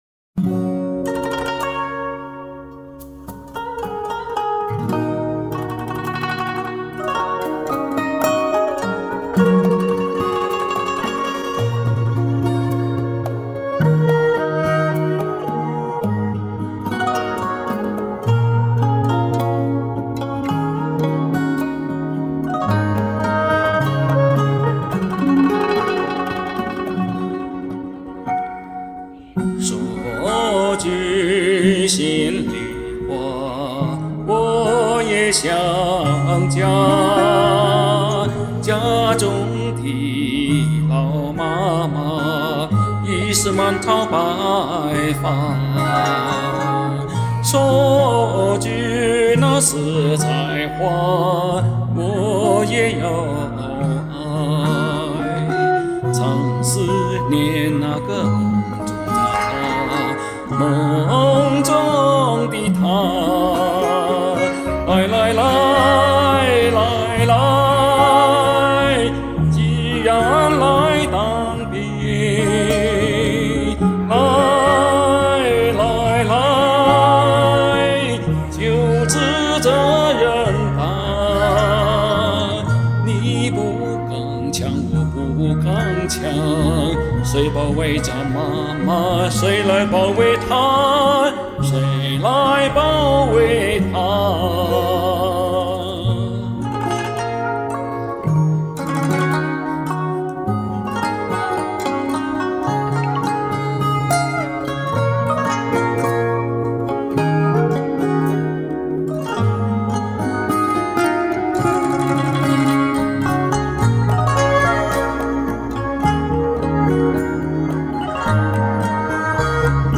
这首军旅歌曲有印象！
舒展大方磁性深情演绎！
大气深情，气息稳！
这版伴奏质量真好